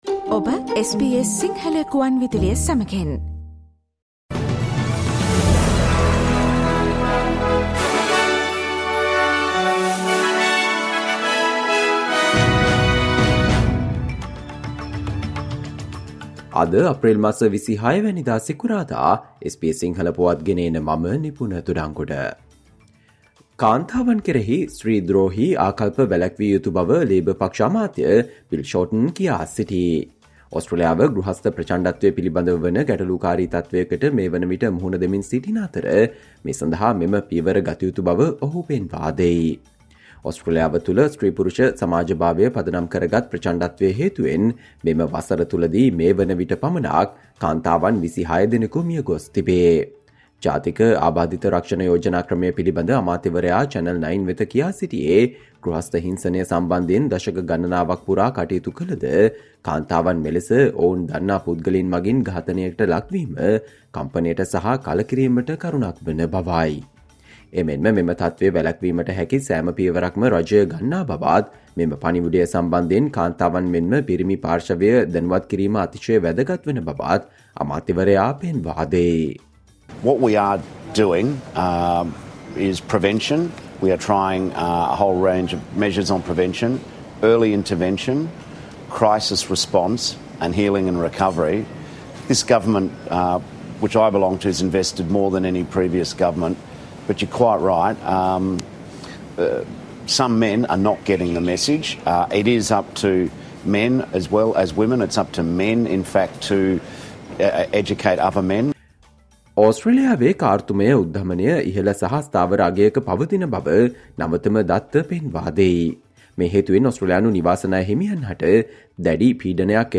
Australia news in Sinhala, foreign and sports news in brief - listen, Friday 26 April 2024 SBS Sinhala Radio News Flash